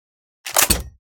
glReload.ogg